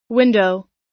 Транскрипция и произношение слова "window" в британском и американском вариантах.
window__us_1.mp3